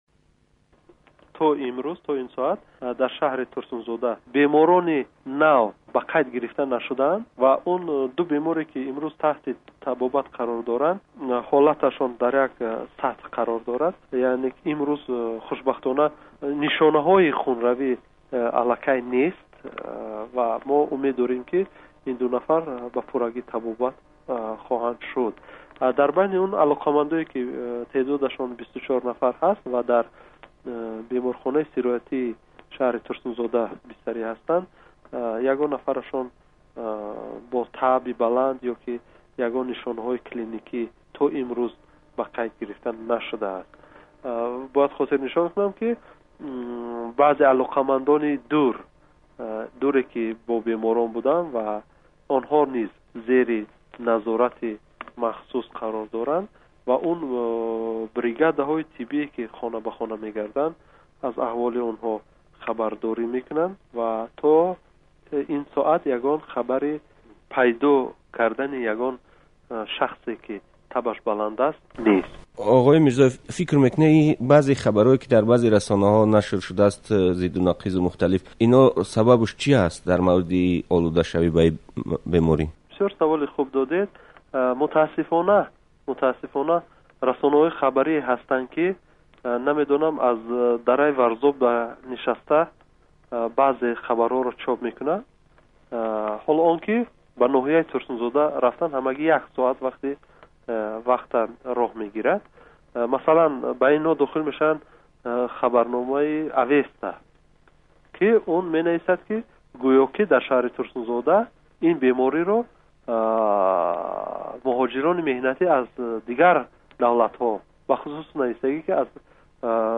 Мусоҳиба бо муовини вазири тандурустӣ